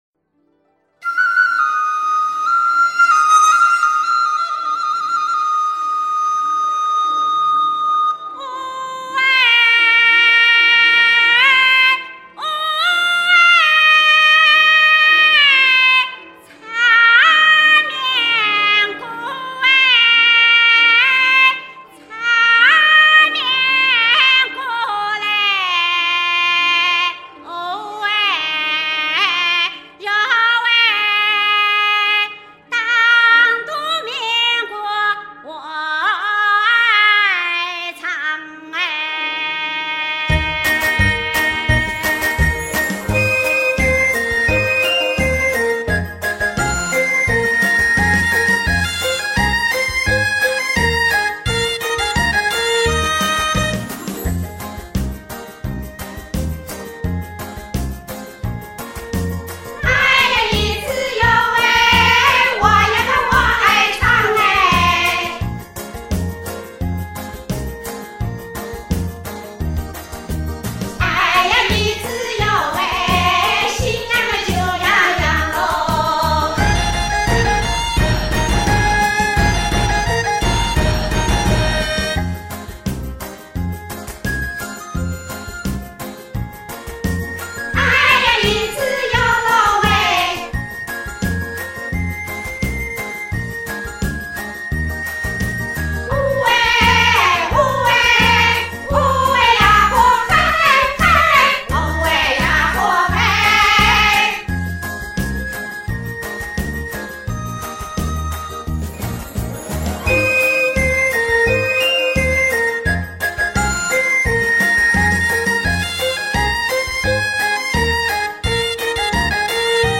当前播放 我爱唱（当涂民歌）-001
民歌